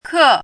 注音： ㄎㄜˋ
ke4.mp3